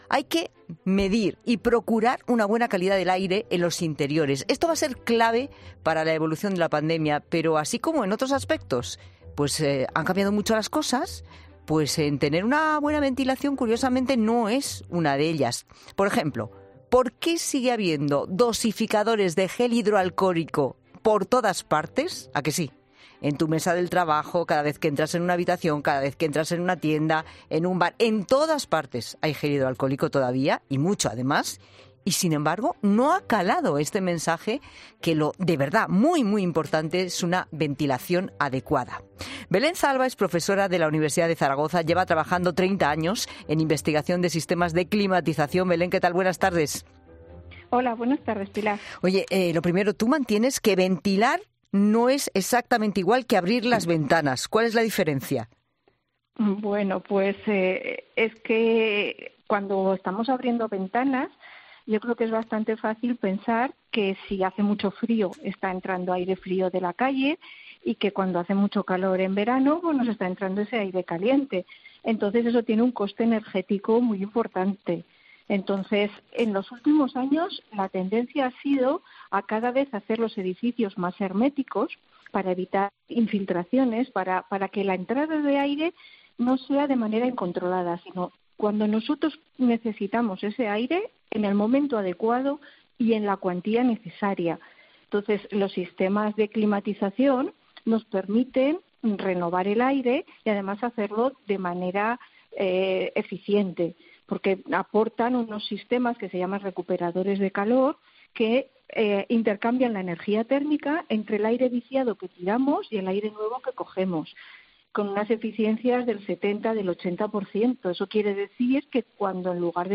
Esta experta ha explicado en ' La Tarde ' la diferencia entre ventilar y abrir las ventanas.